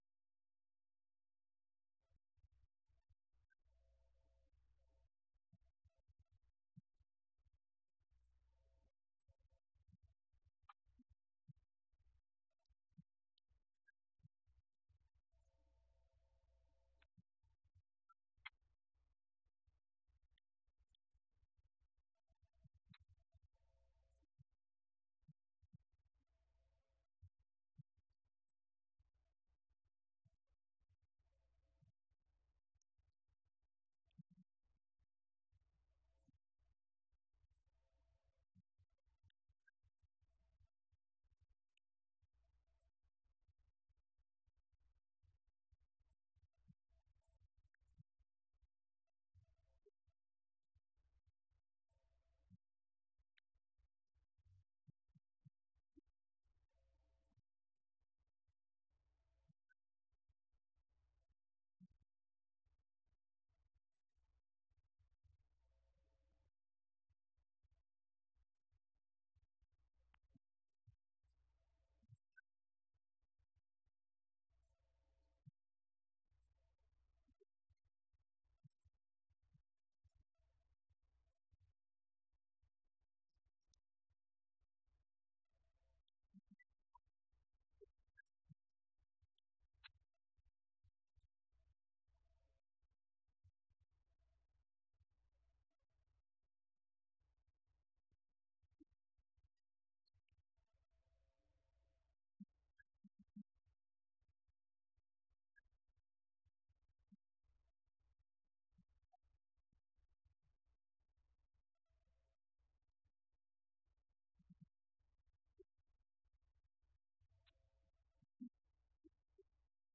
Event: 17th Annual Schertz Lectures
lecture